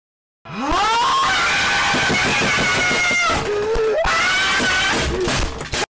Play Korean Gamer Scream - SoundBoardGuy
Play, download and share Korean gamer scream original sound button!!!!
korean-gamer-scream.mp3